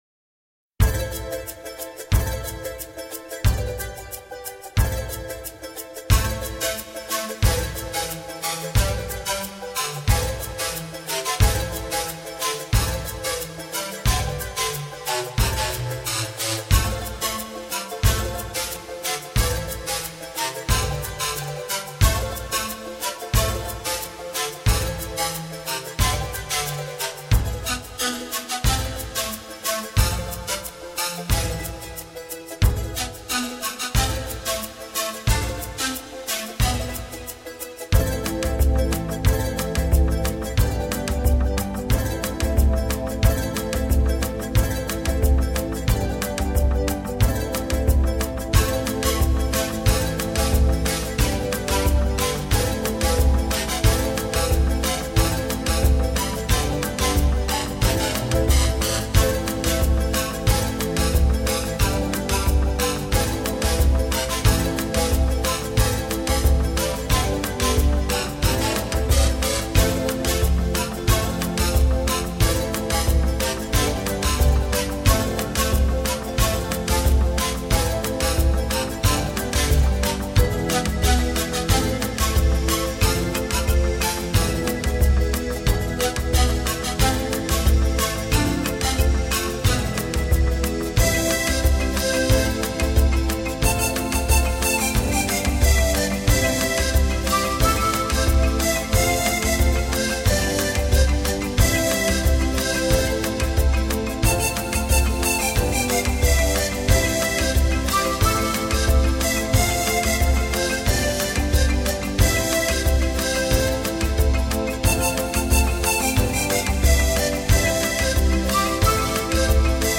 添加了和声，
整张专辑显露出一种更柔和而丰厚的层次，
音乐节奏和细节更为紧凑。